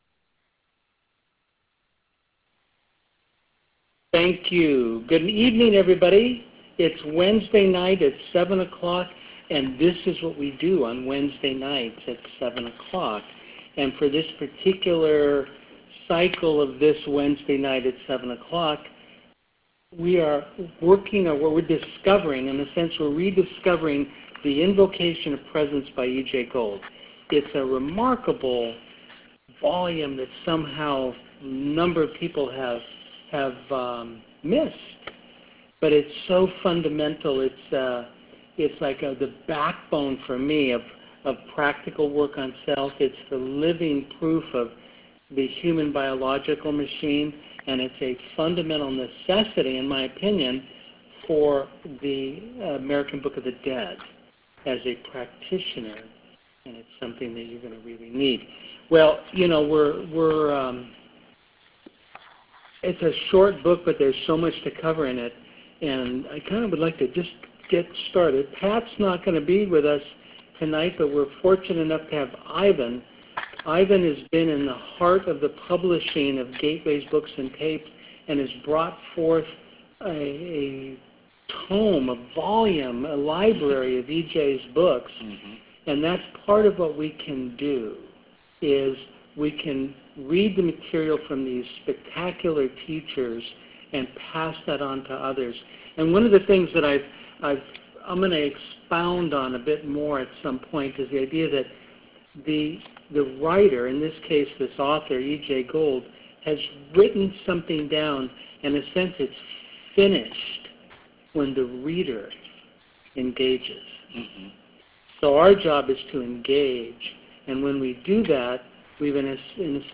Here is the recording from Class Number 2: Class 02 Aug 03 2016